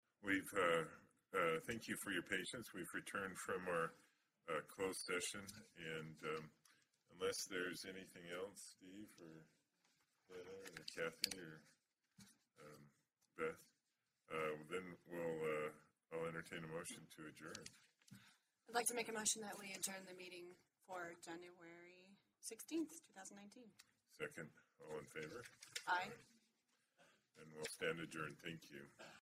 Regular Meeting of the Board of Trustees of the Utah Transit Authority
Meeting